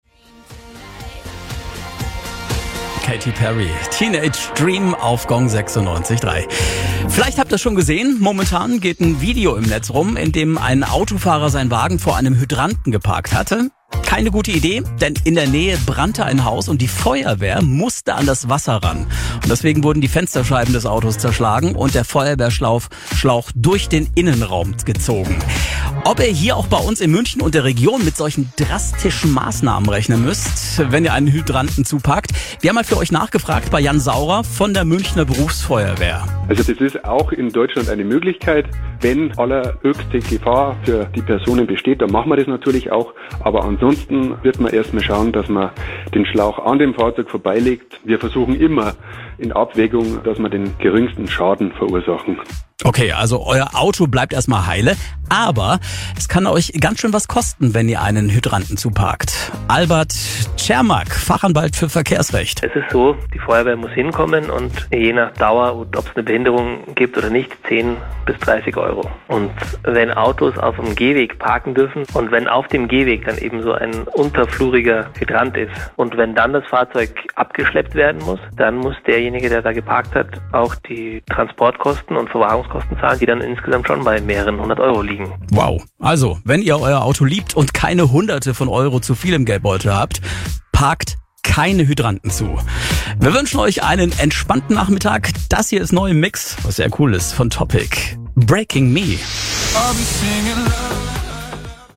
Auszüge aus Radiosendungen